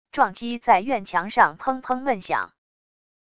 撞击在院墙上砰砰闷响wav音频